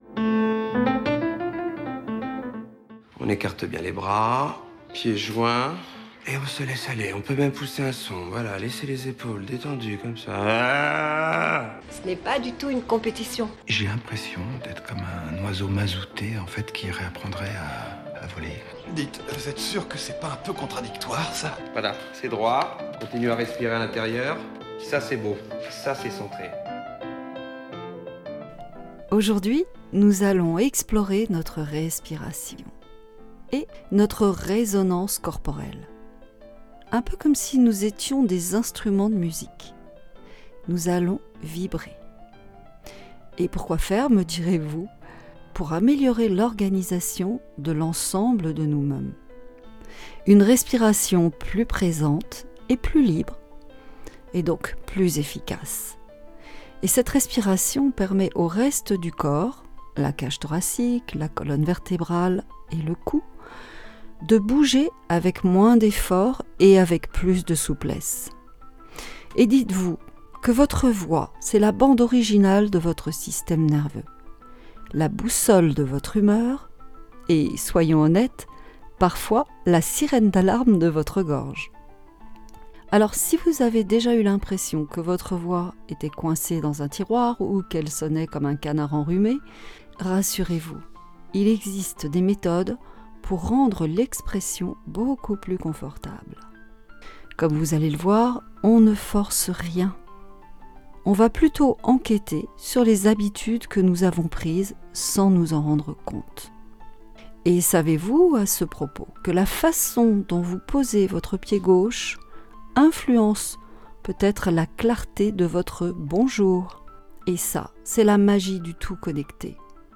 Laissez-vous guider, sans effort et profitez de l'instant et de cette découverte de votre corps, de vos postures et de vos gestes. Pour bien commencer l'année, on respire et on écoute les vibrations intérieures de son corps.